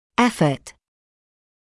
[‘efət][‘эфэт]усилие, попытка; усилия (при неисчисл. значении)